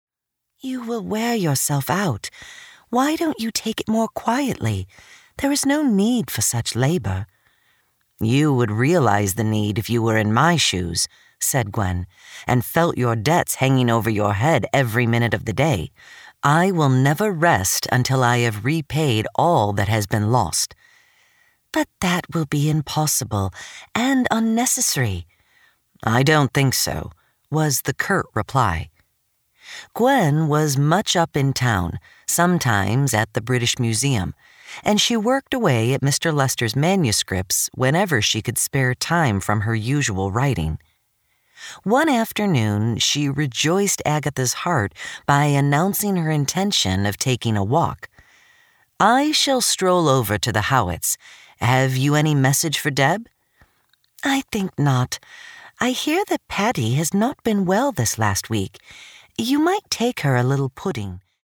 Audiobook: Locked Cupboard, The – MP3 download
This is an audiobook, not a Lamplighter Theatre drama.